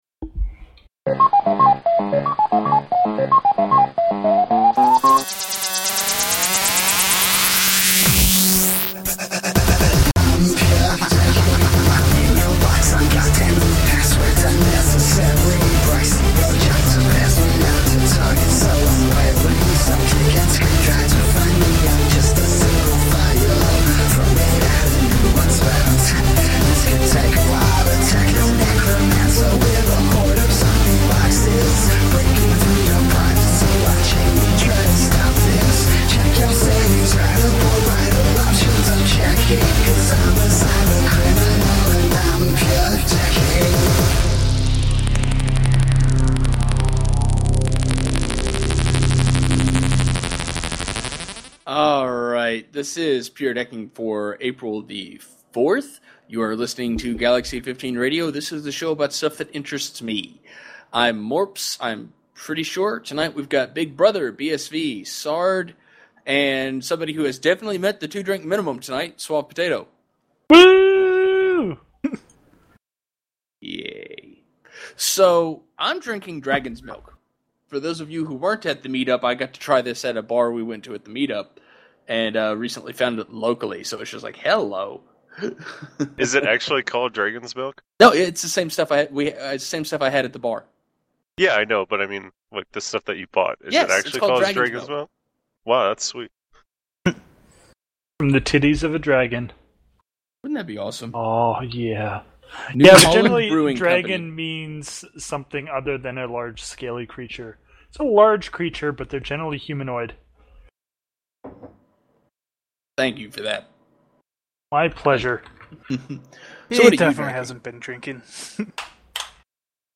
live show for G15